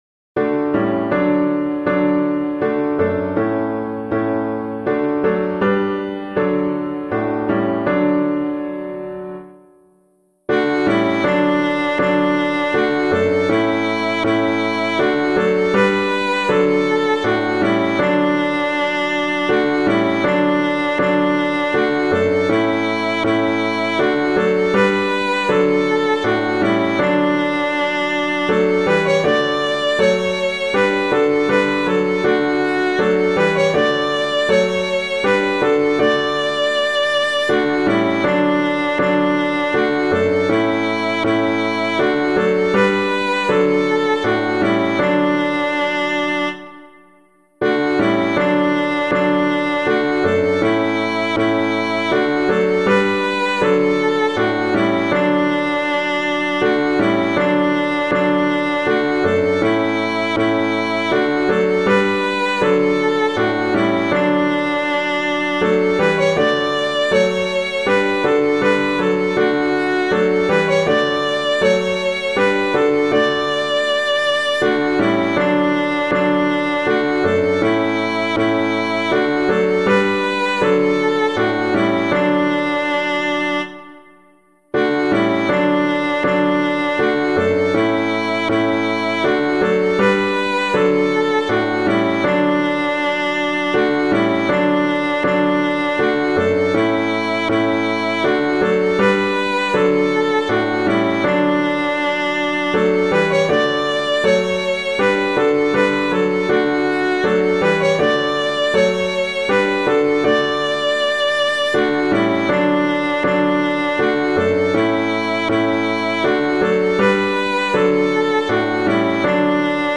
Public domain hymn suitable for Catholic liturgy.
God We Praise You God We Bless You [Idle - NETTLETON] - piano.mp3